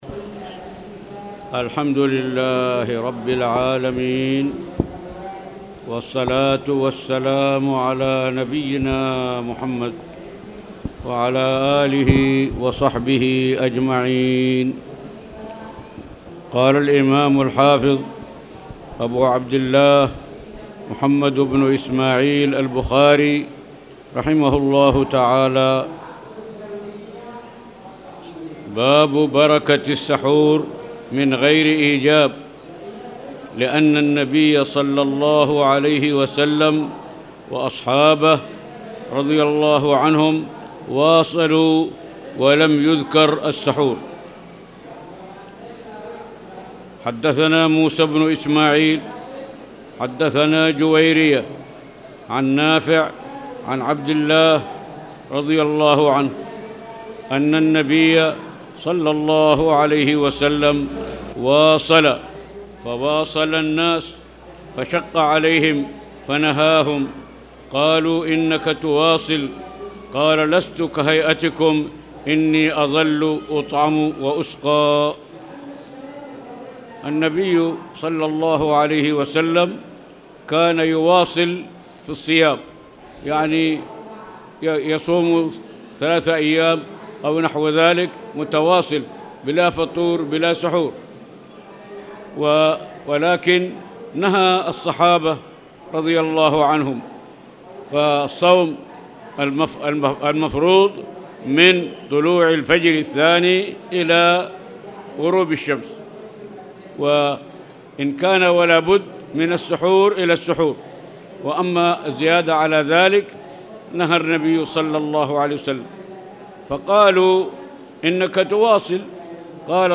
تاريخ النشر ٢٢ ربيع الأول ١٤٣٨ هـ المكان: المسجد الحرام الشيخ